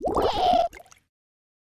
Minecraft Version Minecraft Version snapshot Latest Release | Latest Snapshot snapshot / assets / minecraft / sounds / mob / ghastling / spawn.ogg Compare With Compare With Latest Release | Latest Snapshot
spawn.ogg